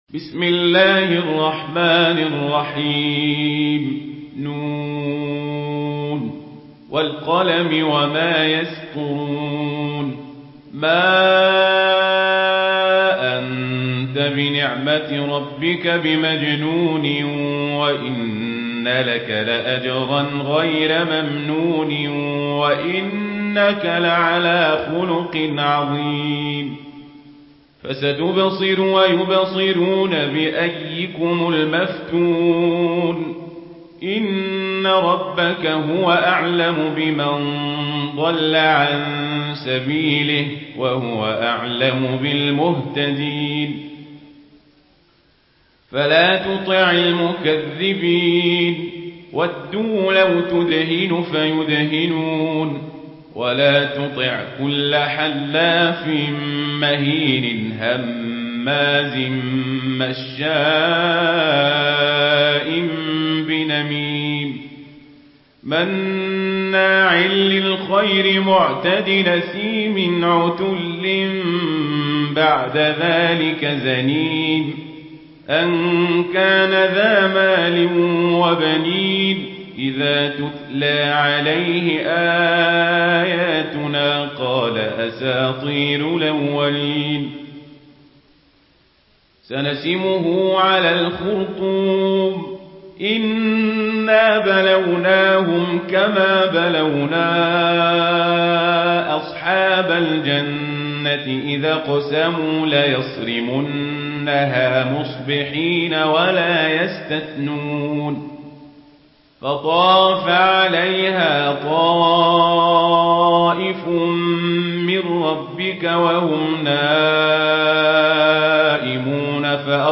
سورة القلم MP3 بصوت عمر القزابري برواية ورش عن نافع، استمع وحمّل التلاوة كاملة بصيغة MP3 عبر روابط مباشرة وسريعة على الجوال، مع إمكانية التحميل بجودات متعددة.
مرتل ورش عن نافع